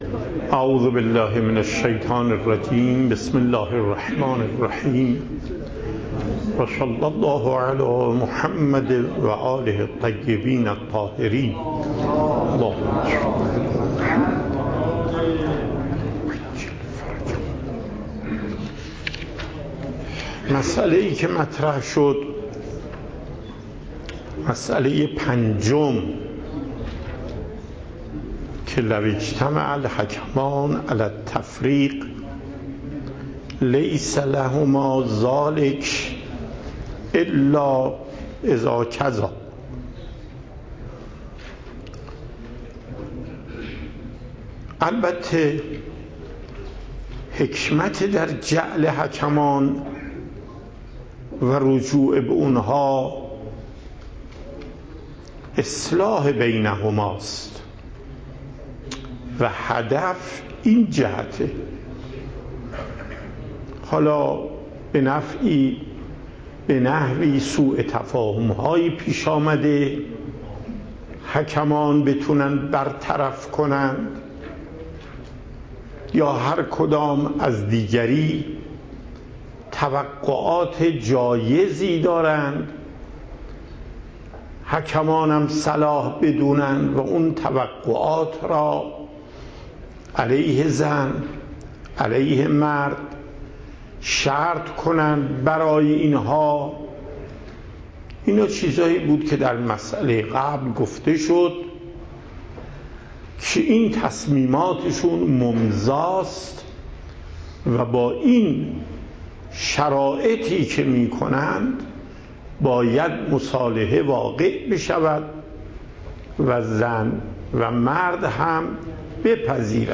صوت درس
درس فقه آیت الله محقق داماد